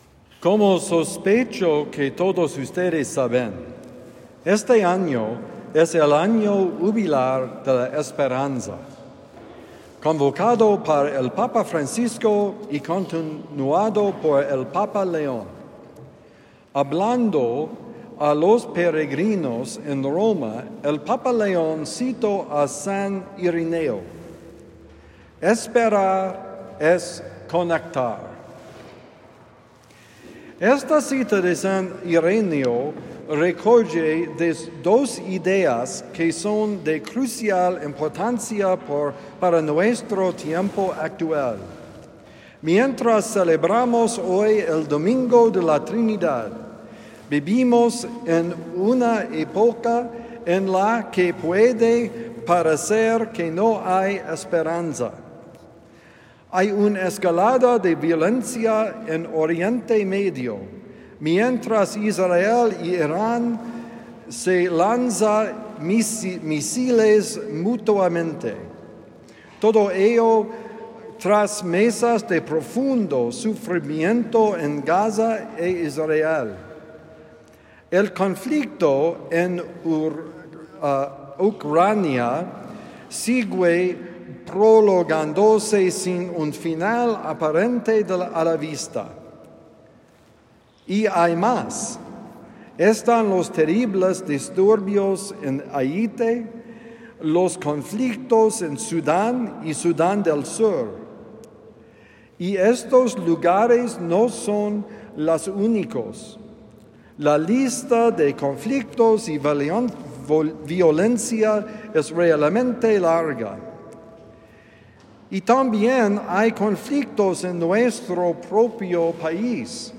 Esperar es conectar: Homilía del domingo 15 de junio de 2025 – The Friar